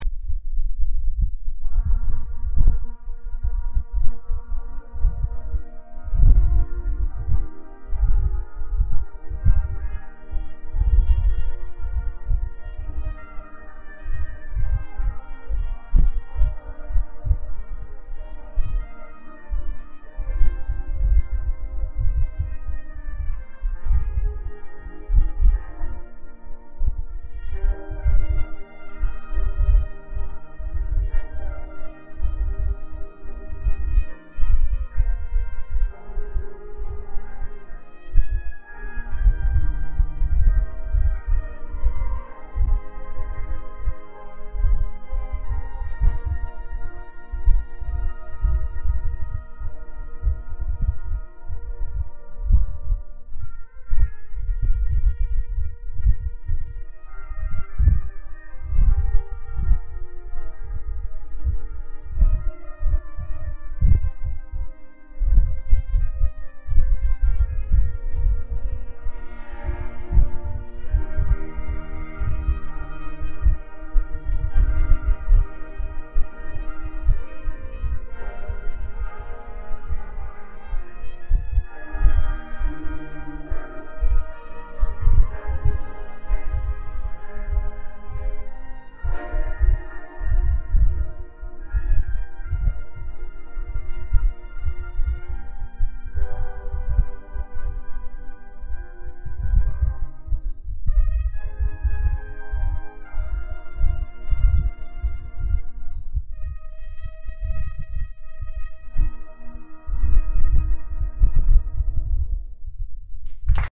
Gabriel's Oboe - Brass Ensemble